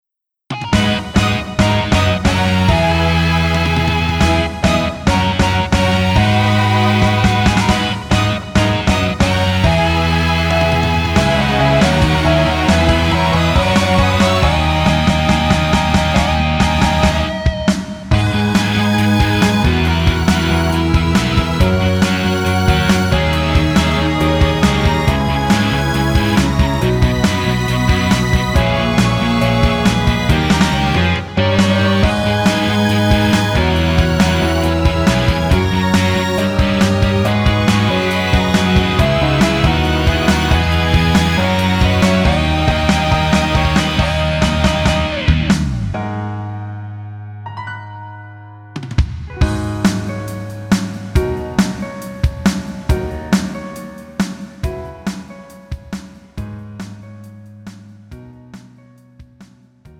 음정 여자키 3:16
장르 가요 구분 Pro MR
Pro MR은 공연, 축가, 전문 커버 등에 적합한 고음질 반주입니다.